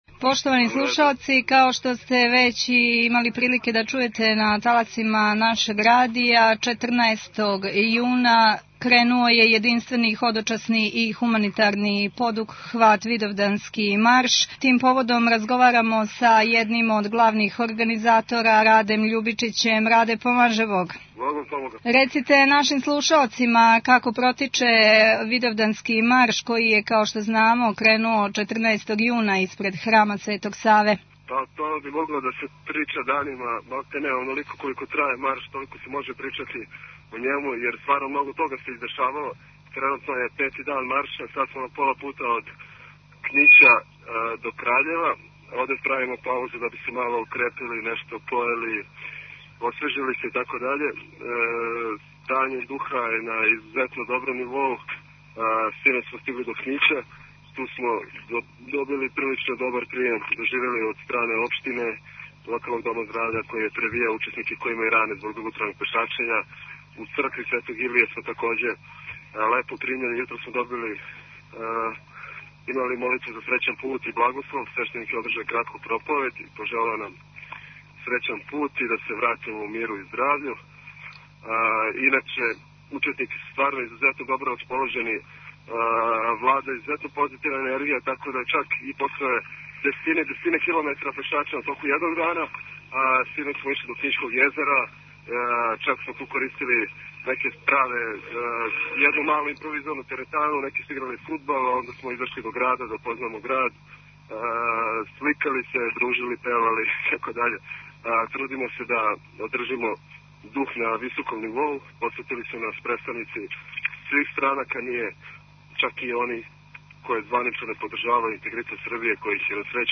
Tagged: Актуелни разговори